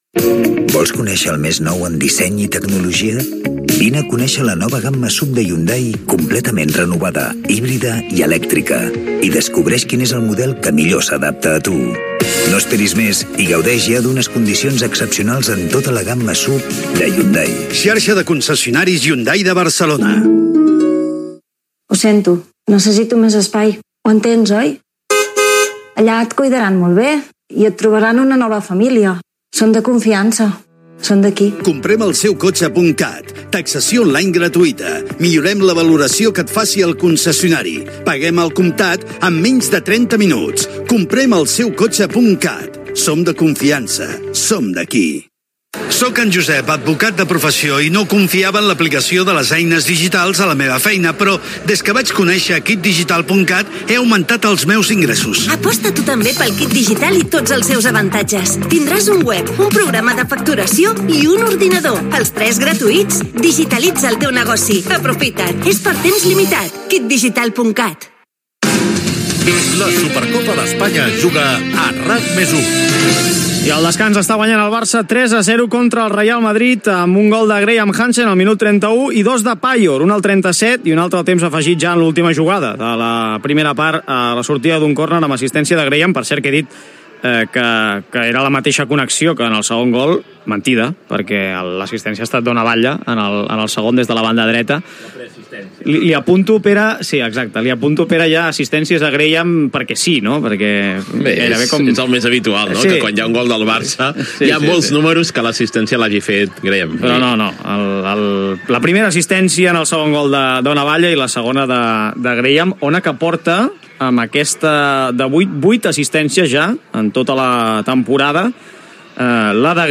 edc3fc11bbab3710e660fad02709f52a42f77a66.mp3 Títol RAC+1 Emissora RAC+1 Cadena RAC Titularitat Privada nacional Nom programa La supercopa d'Espanya es juga a RAC+1 Descripció Transmissió de la final de la Supercopa d'Espanya de Futbol femení entre el Real Madrid i el Futbol Club Barcelona. Publicitat, resum de la primera part, actualitat esportiva,publicitat, valoració de la primera part, narració de les jugades dels primers deu minuts de la segona part Gènere radiofònic Esportiu